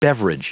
Prononciation du mot beverage en anglais (fichier audio)
Prononciation du mot : beverage